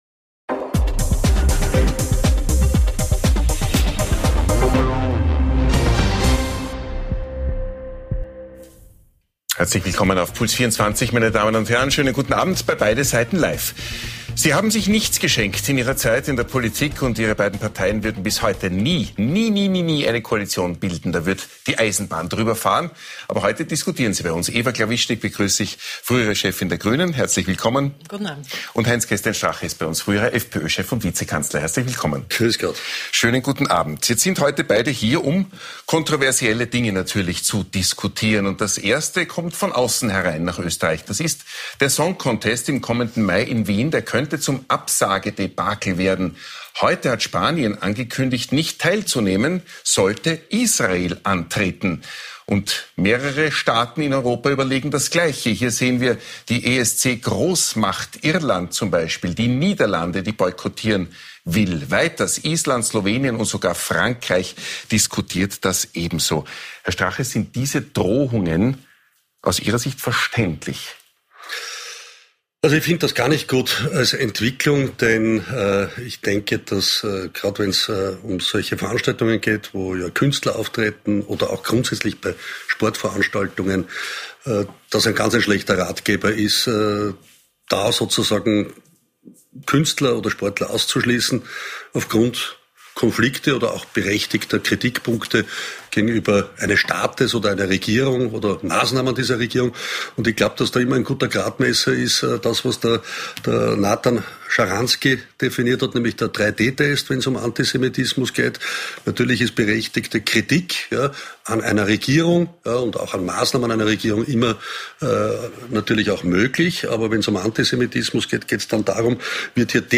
Zu Gast: ehem. FPÖ-Vizekanzler HC. Strache & ehem Grünen-Chefin Eva Glawischnig
Wir beleuchten beide Seiten und präsentieren eine spannende Debatte.